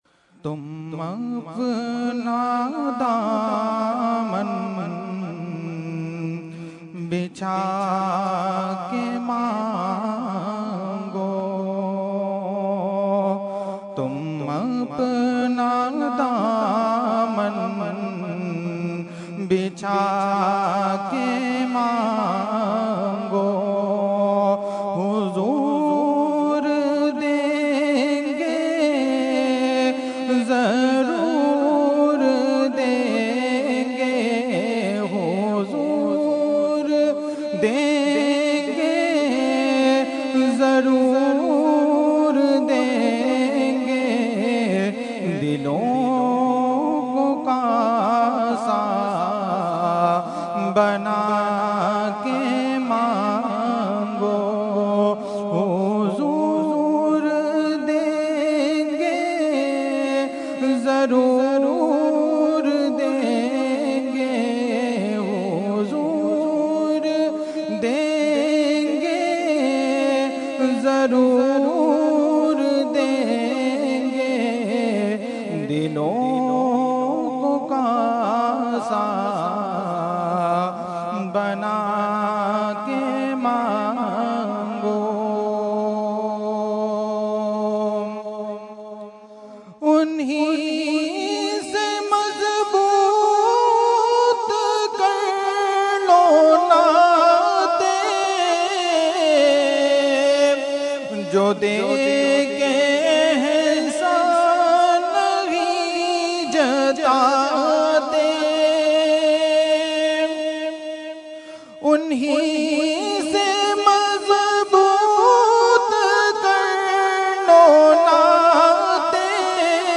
Category : Naat | Language : UrduEvent : Mehfil e Khatm e Quran 2013